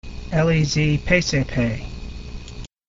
(pronounced – EL-EE-ZEE-PEH-SEH-PEH).